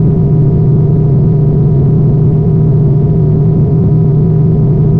spaceEngineLow_000.ogg